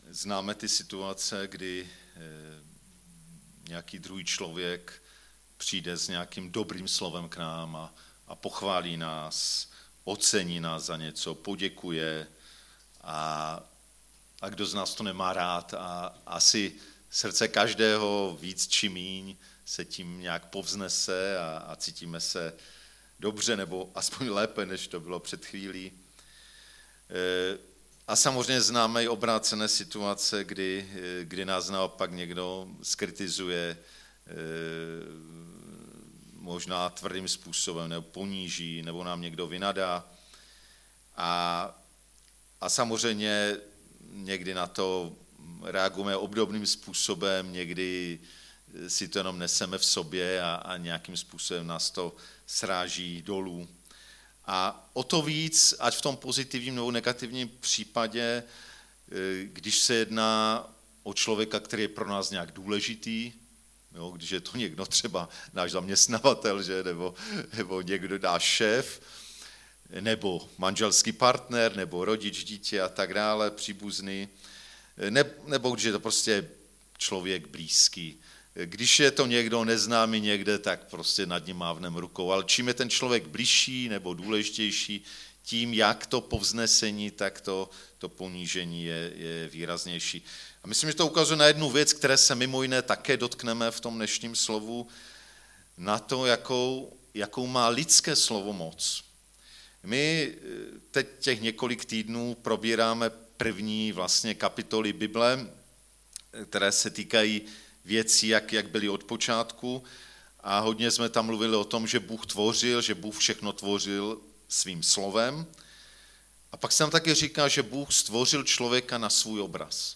6. díl ze série kázání "Ve světle počátku", Gn 2,18-25
Kategorie: nedělní bohoslužby